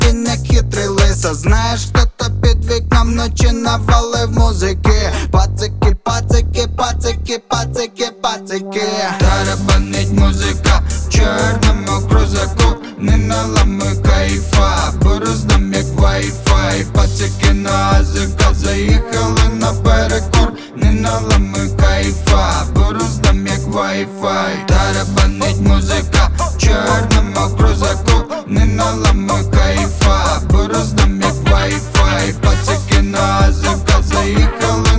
Жанр: Рэп и хип-хоп / Иностранный рэп и хип-хоп / Украинские